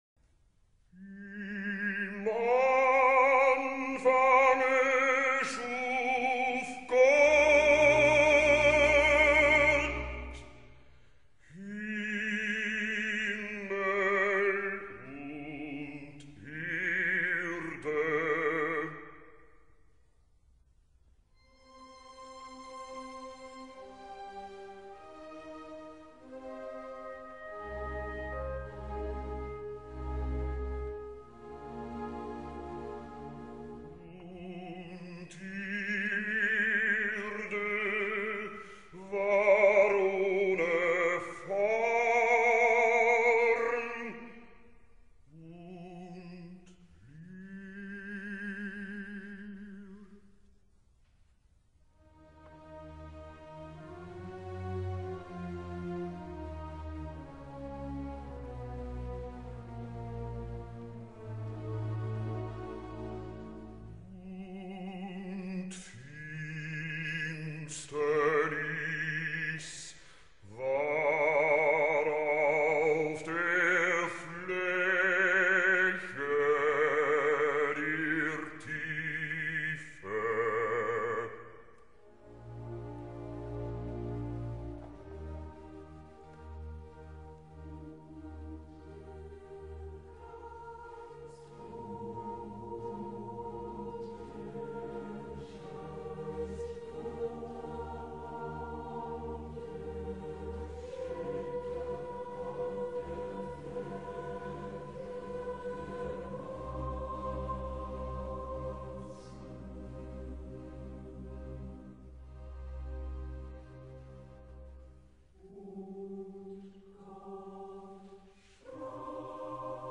Haydn, ponctuée de trompette et tambour, à la JS Bach, Fra Angelico substitue le calme apaisé, presque olympien, de deux femmes qui savent leurs destins embrasés par le Verbe et s'y soumettent avec une sérénité incroyable pour nos esprits modernes embourbés de tumultes.